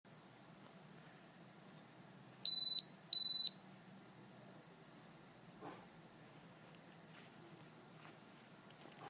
At some toll plazas, tags will emit the following sound if the account balance is low – top up as soon as possible
rms-two-beep.mp3